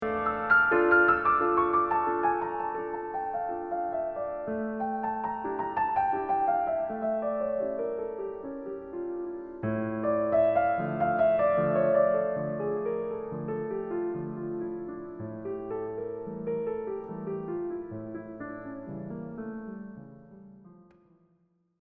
Did you hear that the “sighs” came back after the huge crescendo? Now they are more sad, like someone who lost the battle…